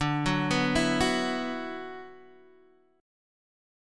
D6add9 chord